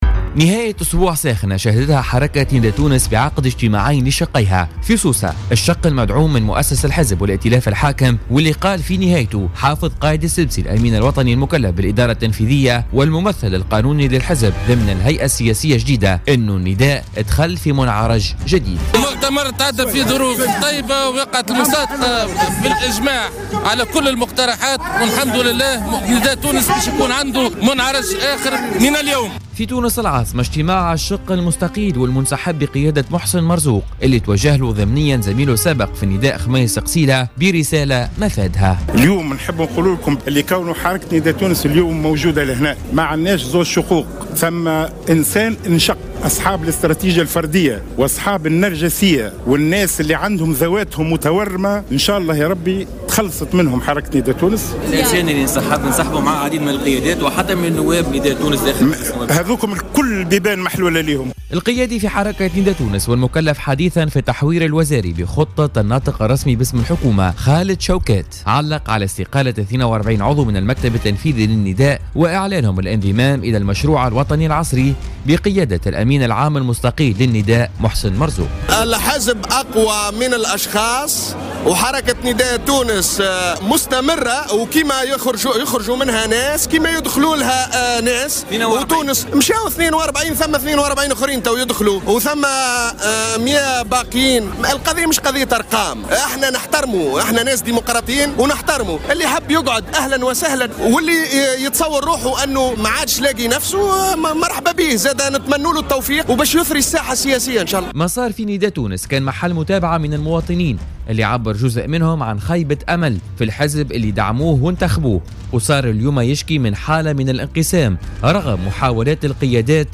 رفض الأمين العام المستقيل من حزب نداء تونس محسن مرزوق ضيف بوليتيكا اليوم الإثنين 11 ديسمبر 2016 التعليق على تصريحات كل من خميس قسيلة وخالد شوكات على هامش مؤتمر سوسة بخصوصه مؤكدا أن لايريد التعليق وليس له الوقت ليخسره في مثل هذه الترهات وأنه يفضل الترفع على حد قوله.